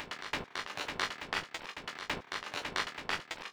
• techno house mid robotic perc crispy.wav
techno_house_mid_robotic_perc_crispy_Ch2.wav